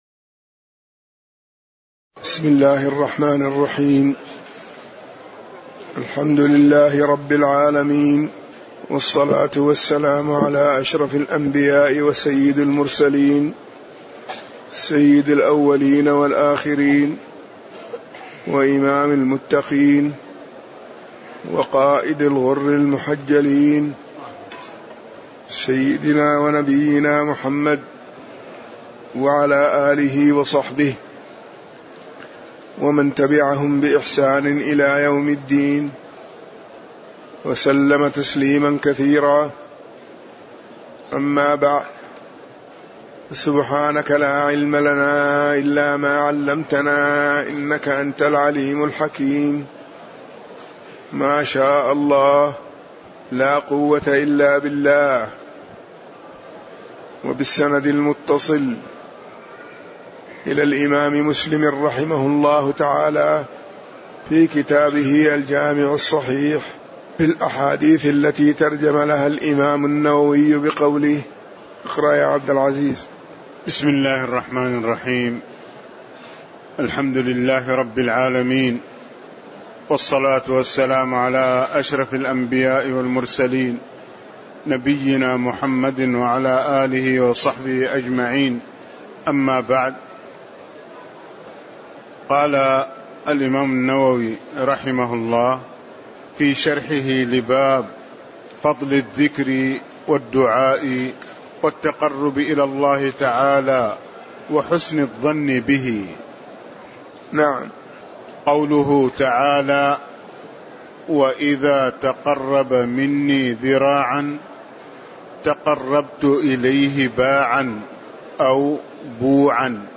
تاريخ النشر ٢٨ ربيع الأول ١٤٣٨ هـ المكان: المسجد النبوي الشيخ